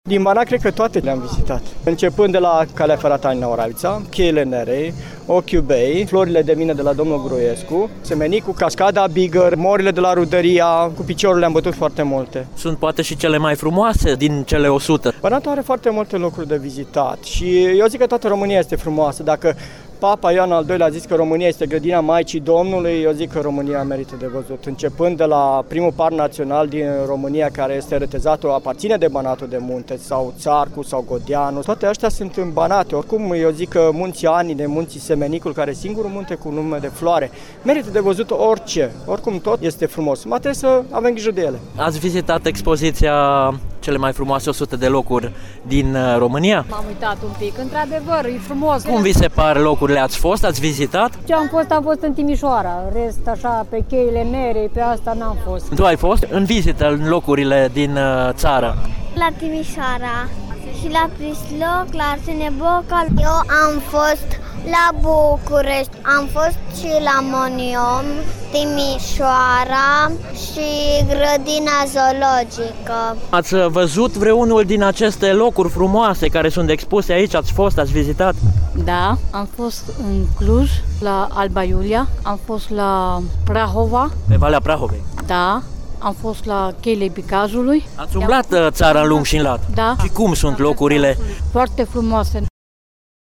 Cetățenii, veniţi cu mic cu mare, din toate colţurile județului Caraș-Severin au admirat imaginile, şi au declarat reporterilor Radio România Reşiţa că au vizitat cele mai multe dintre cele mai frumoase locuri din ţară.